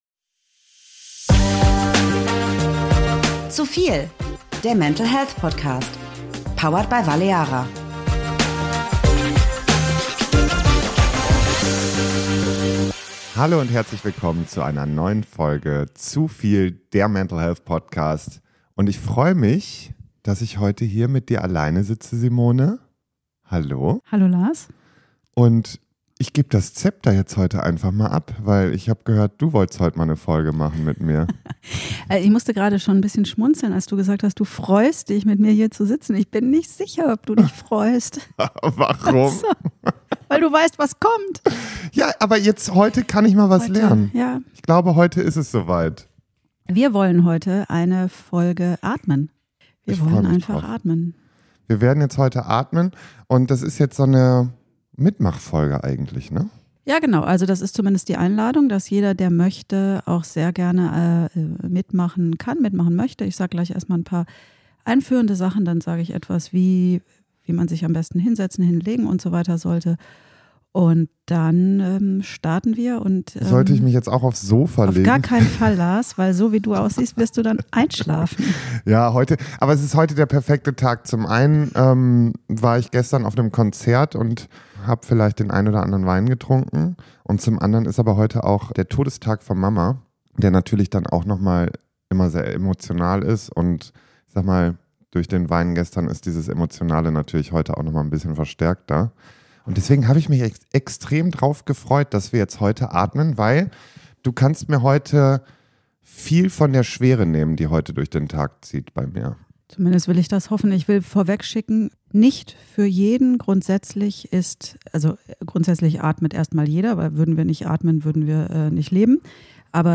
Im Anschluss geht es direkt in die Praxis: eine ruhige, alltagstaugliche Atemübung, die dir helfen kann, Stress loszulassen, deinen Körper besser zu spüren und für einen Moment aus dem „Zu viel“ auszusteigen.